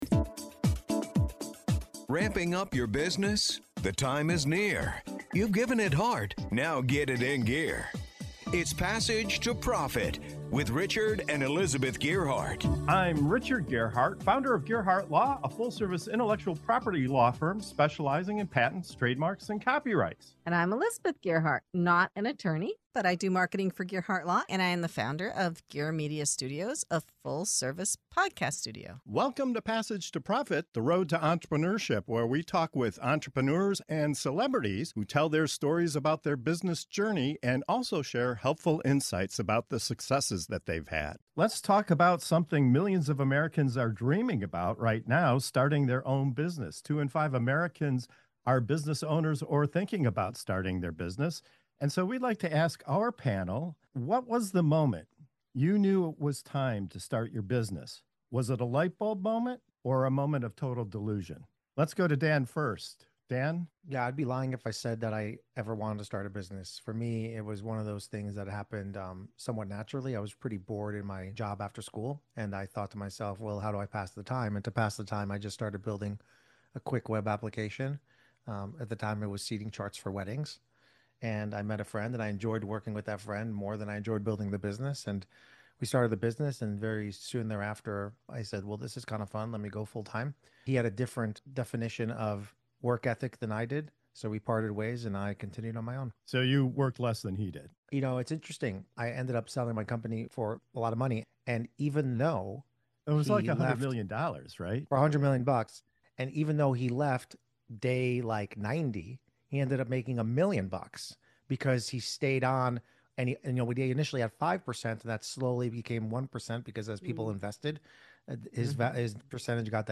Ever wonder what really sparks someone to start a business—vision, boredom, or pure delusion? In this episode, our panel of entrepreneurs shares their wildly different "aha" moments—from a side project that turned into a $100 million exit to childhood dreams of running a café under a bridge.